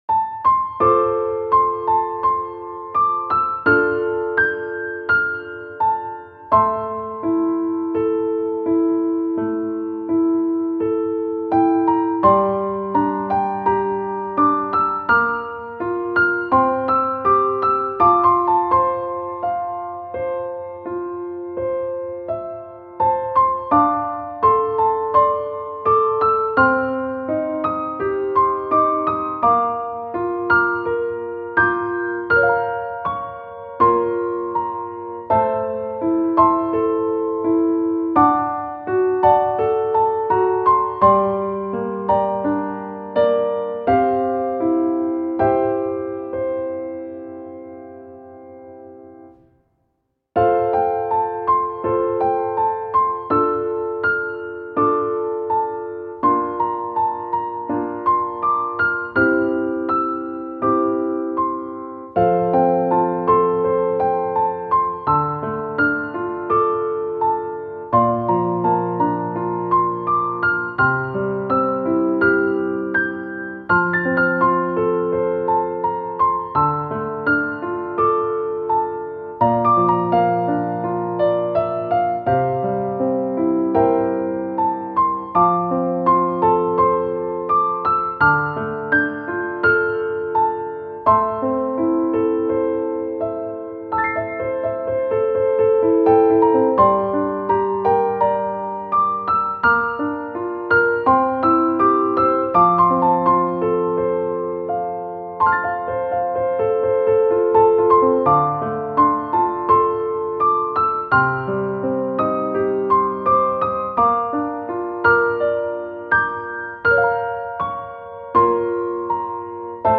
ogg(R) - さわやか 瑞々しい 癒し
みずみずしい粒のような音符。